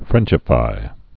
(frĕnchə-fī)